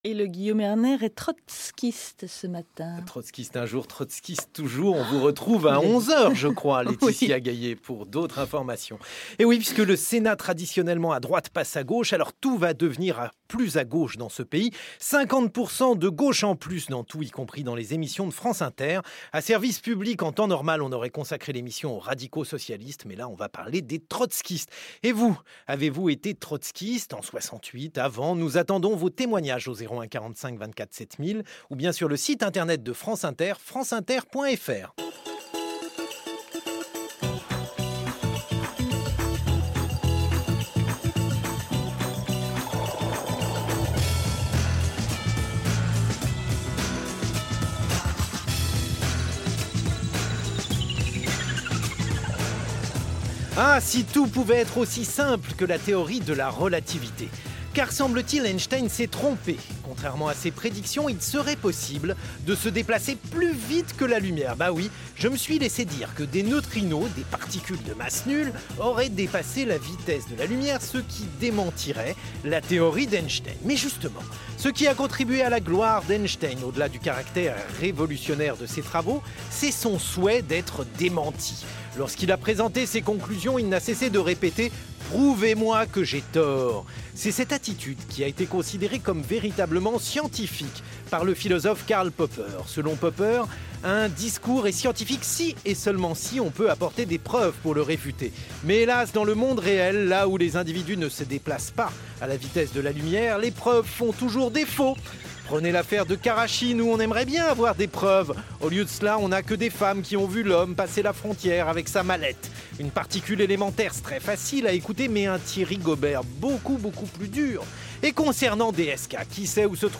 L'émission de radio Service Public de France Inter du lundi 26 septembre 2011 avait pour sujet principal le trotskysme et le débat a été mené par
Pour parler de l’héritage spirituel de Léon Trotsky, Guillaume Erner reçoit un ex-trotskyste : Henri Weber et un toujours-trotskyste, Alain Krivine.